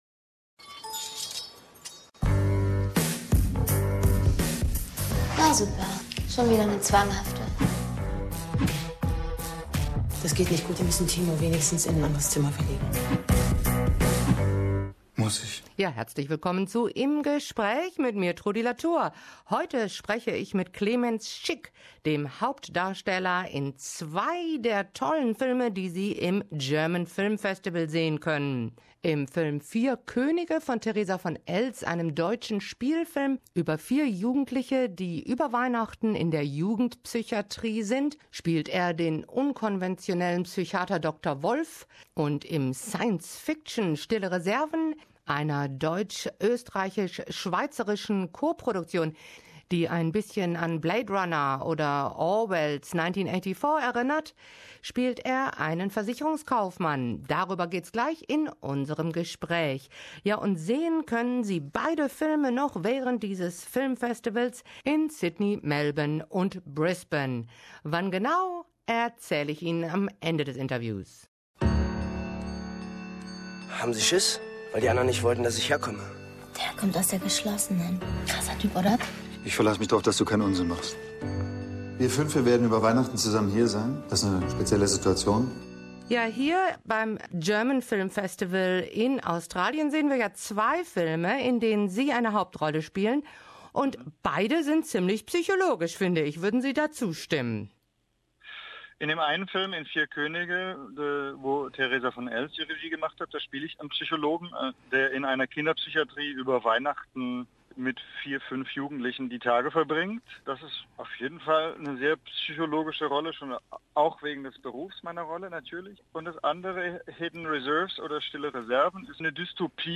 Im Gespräch: Clemens Schick Gast beim Filmfest